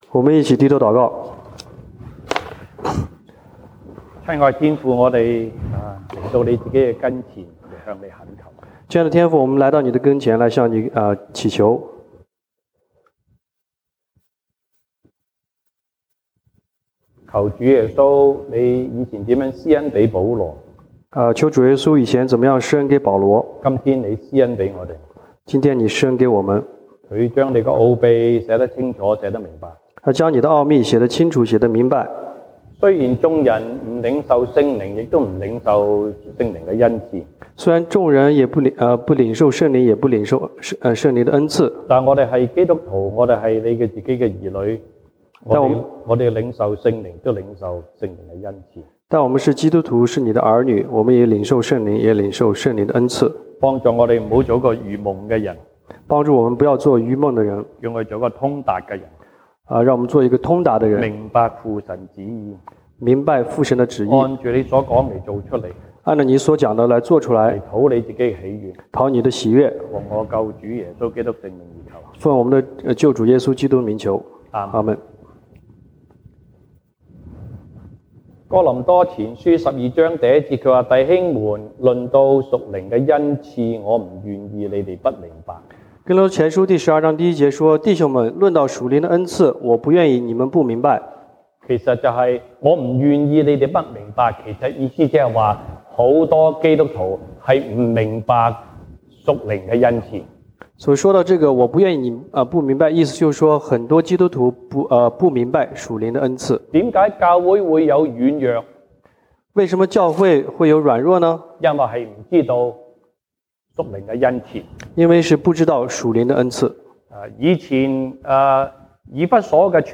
西堂證道(粵語/國語) Sunday Service Chinese: 聖 靈 的 工 作